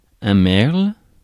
Ääntäminen
Ääntäminen France: IPA: [mɛʁl] Haettu sana löytyi näillä lähdekielillä: ranska Käännös Substantiivit 1. mirlo {m} Suku: m .